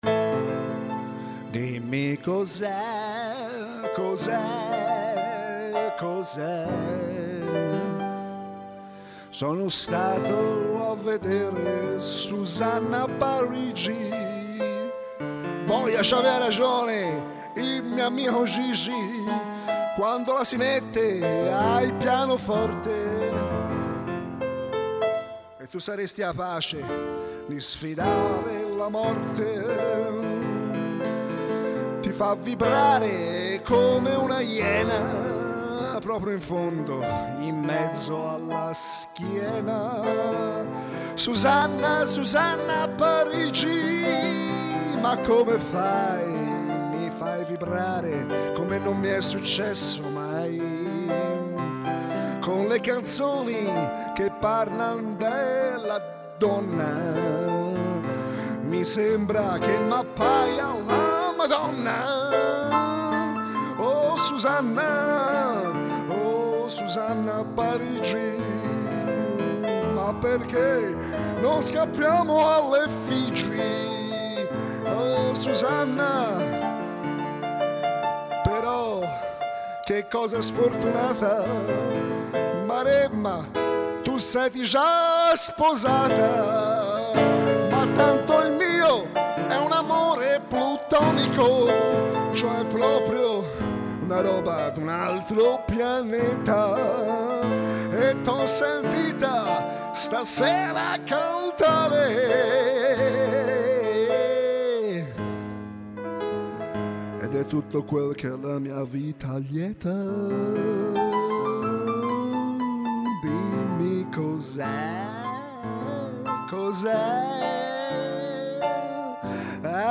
Ho impiegato molto più tempo a scrivere questa descrizione che a creare la canzone, che in effetti è completamente improvvisata, tentando malamente di ricreare il suo raffinatissimo stile pianoforte e voce, non per ambizione di imitarla, ma per un segno di pura ammirazione, come si dice, m'è sgorgata da i' core!